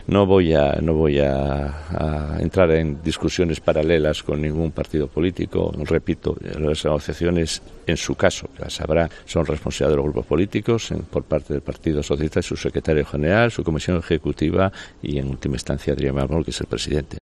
"Las negociaciones son responsabilidad de los grupos políticos", asegura Cofiño en declaraciones a COPE Asturias, recalcando que "por parte del grupo socialista, su secretario general, su comisión ejecutiva y, en última instancia, Adrián Barbón que es el presidente".